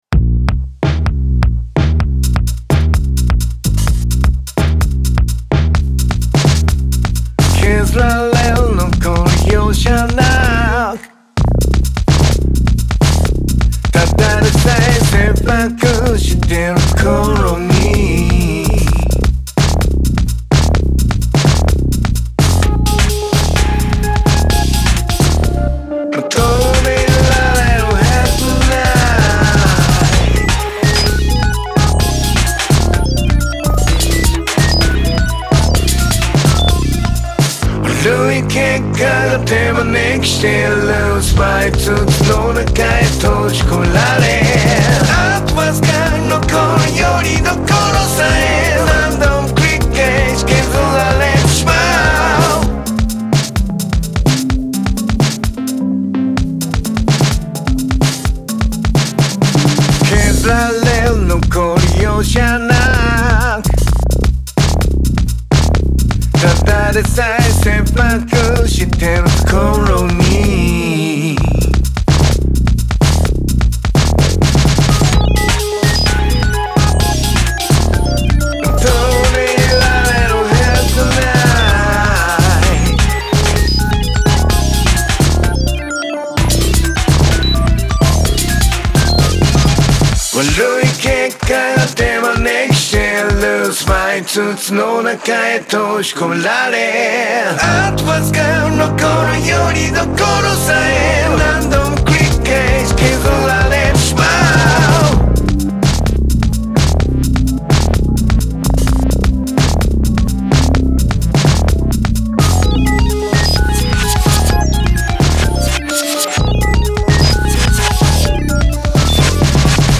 毎日1曲、新曲つくってアレンジ＆録音したものを日々配信中。